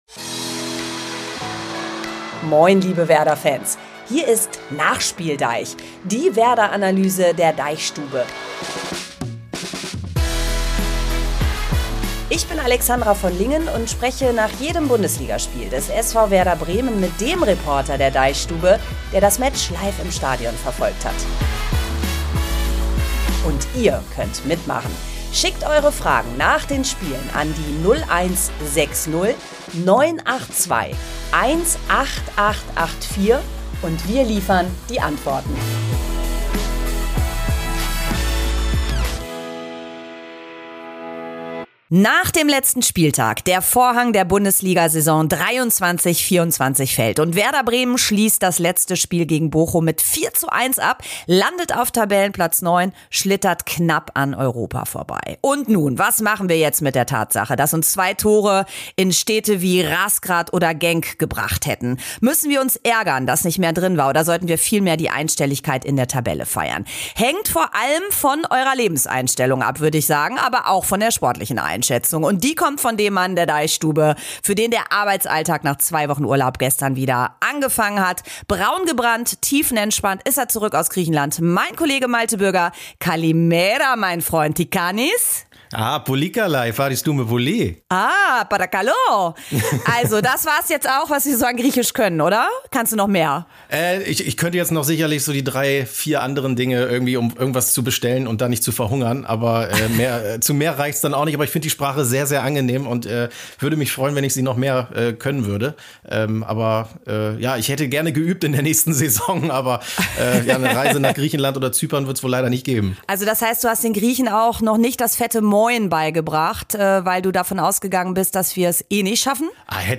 Das große Saisonfazit mit Ausblick auf die neue Spielzeit! NachspielDEICH ist ein Fußball-Podcast der DeichStube.